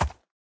sounds / mob / horse / soft1.ogg